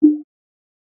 合成された水滴音のような効果音。